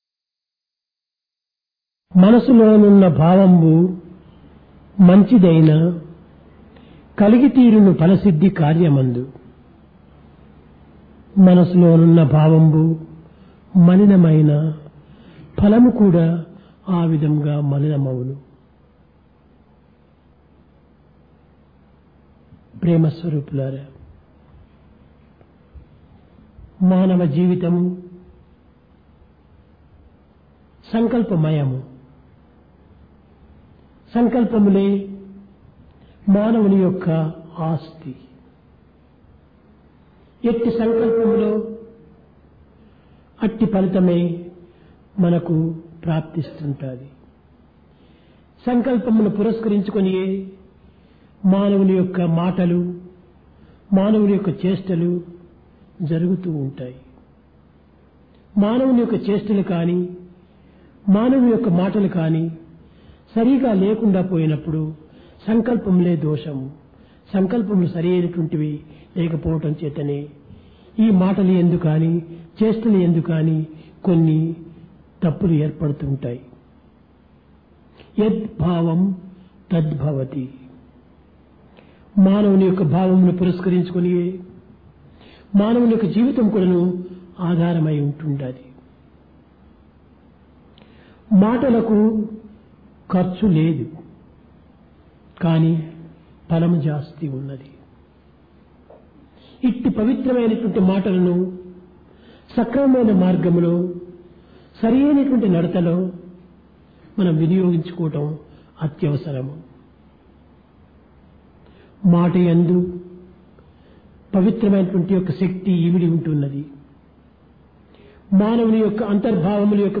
Divine Discourse | Sri Sathya Sai Speaks
Divine Discourse of Bhagawan Sri Sathya Sai Baba